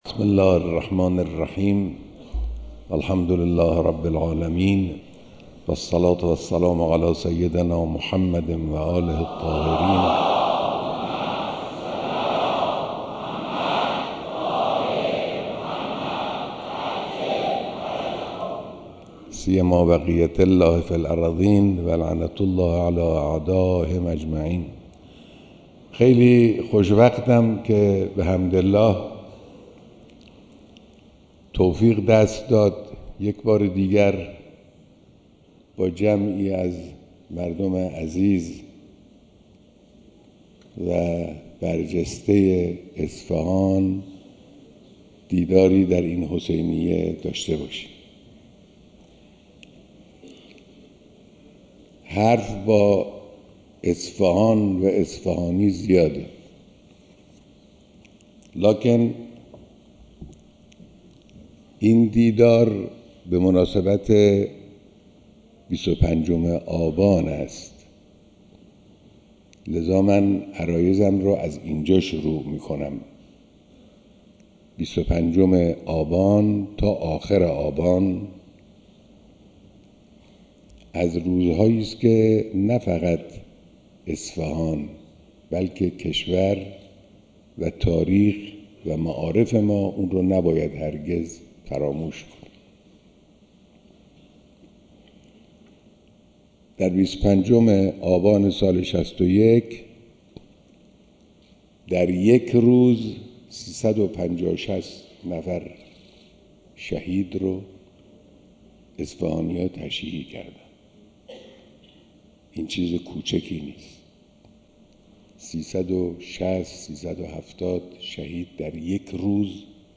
بیانات در دیدار مردم اصفهان